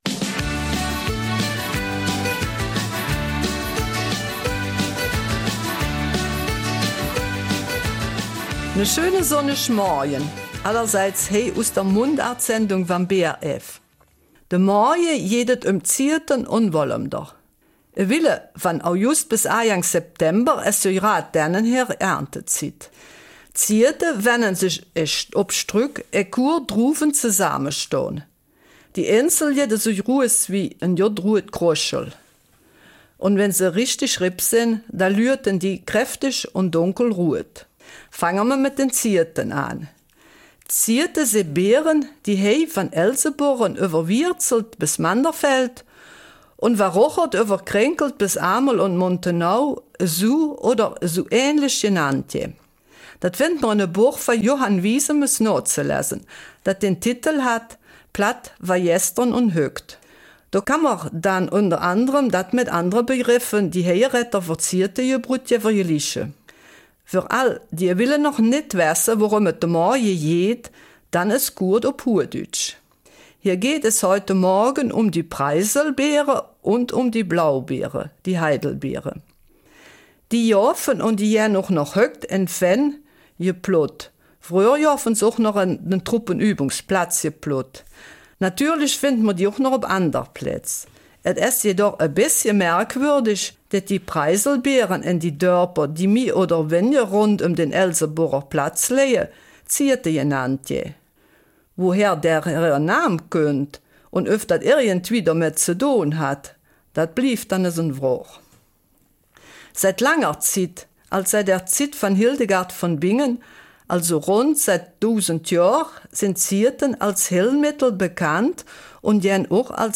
In dieser Mundartsendung geht es um Waldbeeren, besonders um die Preisel- und Blaubeeren, die im August reif sind und im Wald gepflückt werden können.